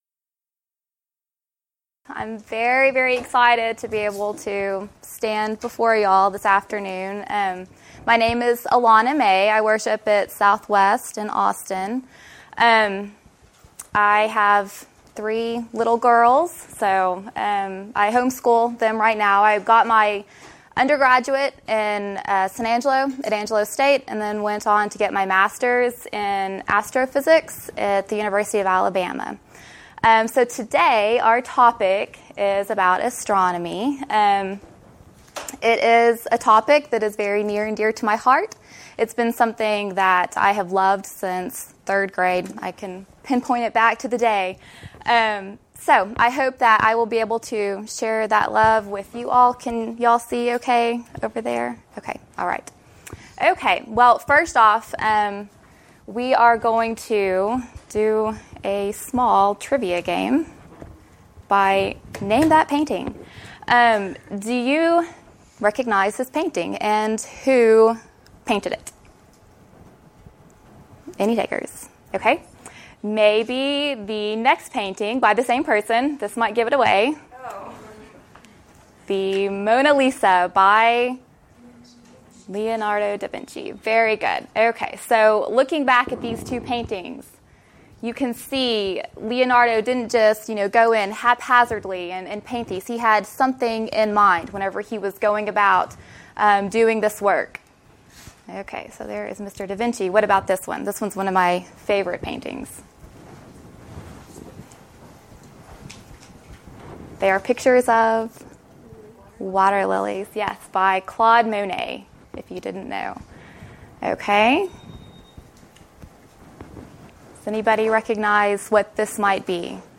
Event: 2014 Discipleship U
lecture